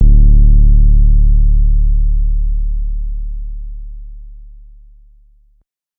808 JustBlaze Distorted.wav